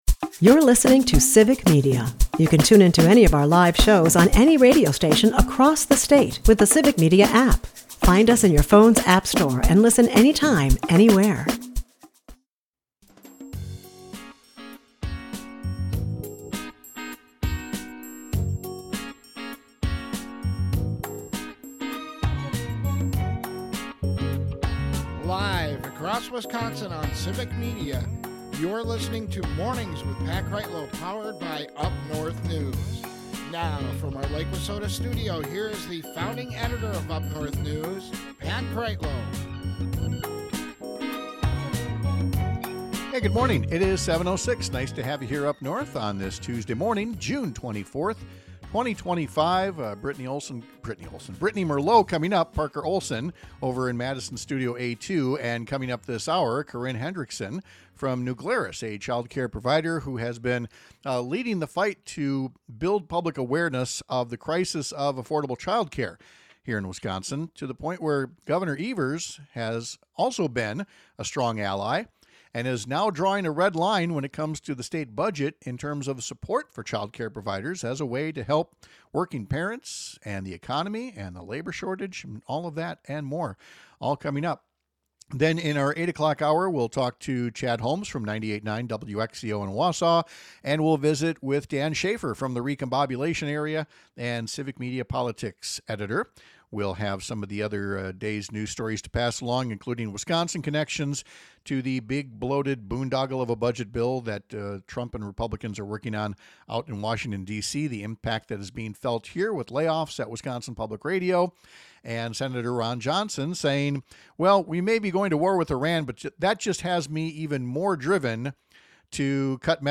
One of the more recent ones is now officially at the center of the state budget debate, after Gov. Tony Evers said he would not sign a state budget bill that doesn’t include funding for a program that addresses the shortage of affordable childcare in Wisconsin. We’ll talk to a daycare provider who’s been leading the charge on making this issue more visible.